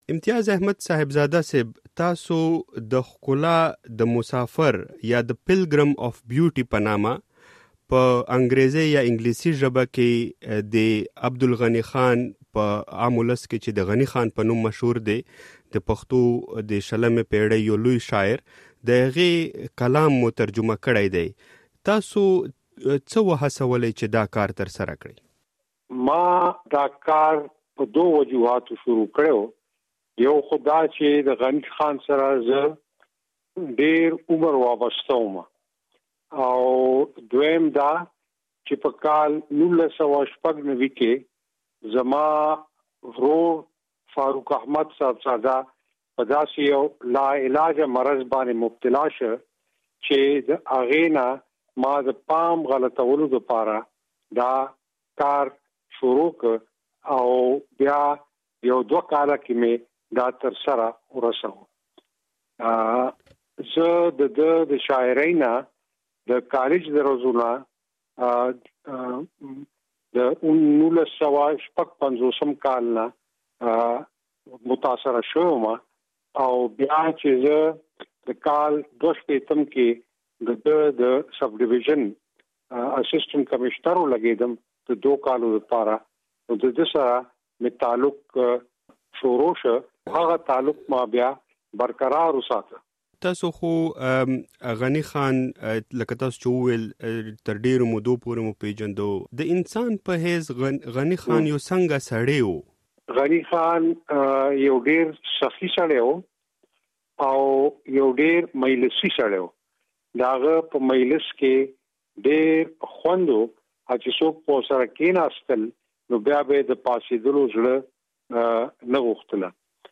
مرکه کړې ده.